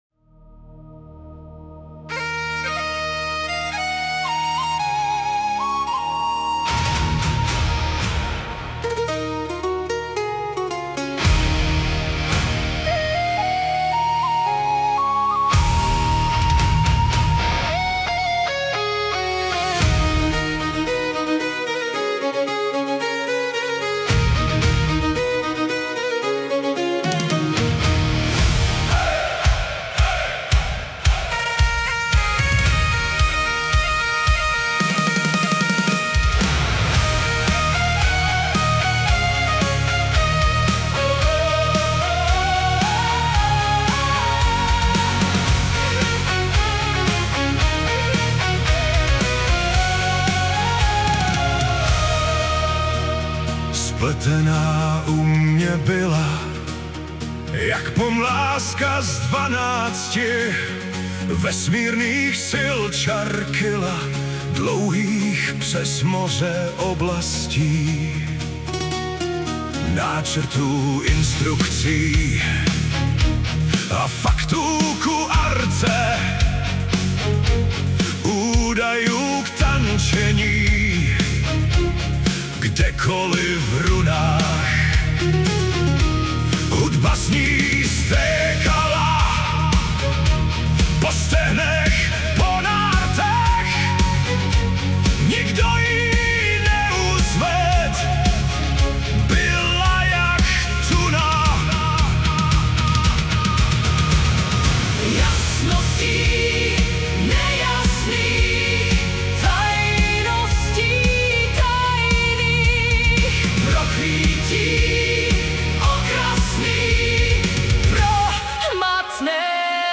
Fantasy